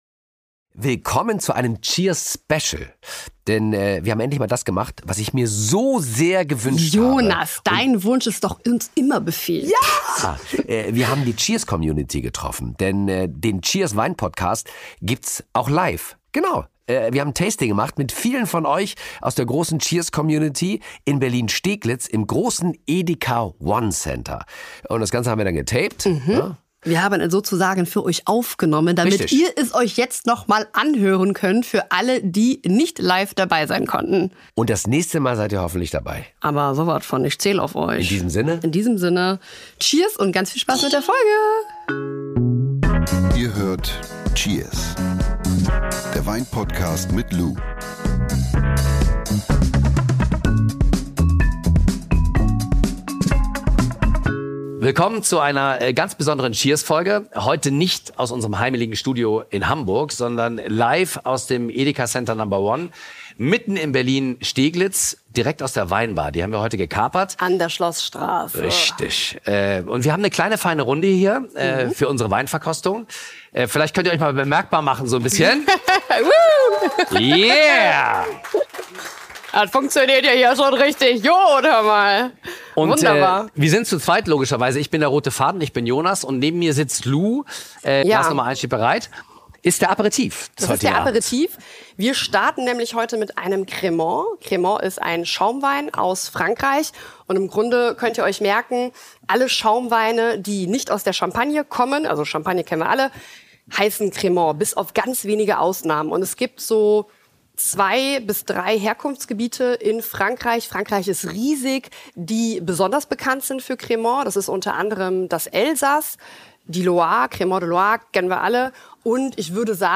Diese Folge haben wir live im Edeka Center No. 1 in Berlin Steglitz aufgenommen und gemeinsam mit der Cheers! Community verschiedene Weinstile verkostet.